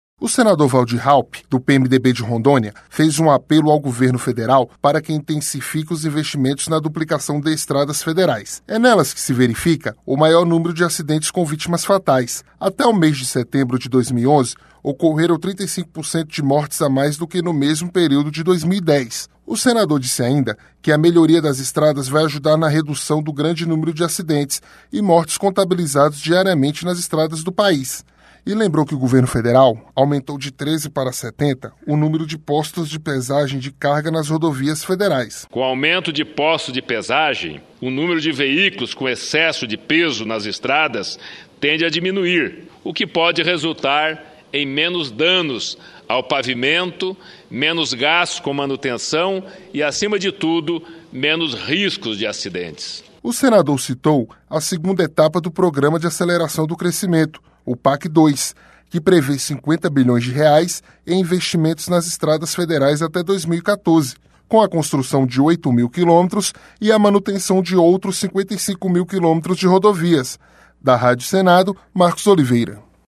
O Senador Valdir Raupp, do PMDB de Rondônia, fez um apelo ao Governo Federal para que intensifique os investimentos na duplicação de estradas federais.